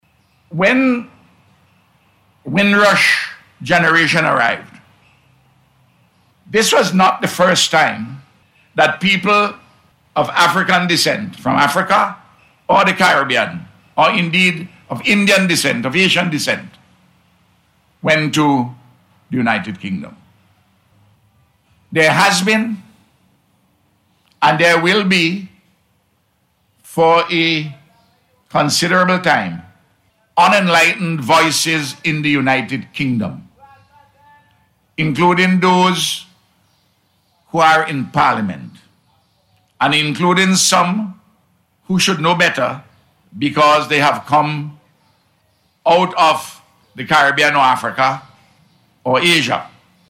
A Flag Raising Ceremony was held at the Administrative Building in Kingstown today, to commemorate the Windrush 75th milestone.
Prime Minister, Dr. Ralph Gonsalves was among officials to address the ceremony this morning.